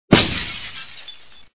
Glass01.wav